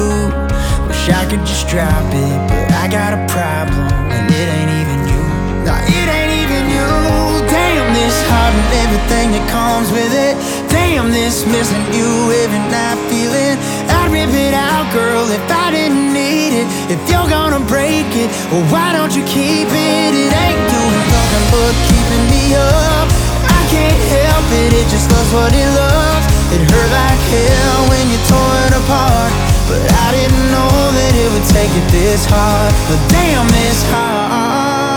2024-01-26 Жанр: Кантри Длительность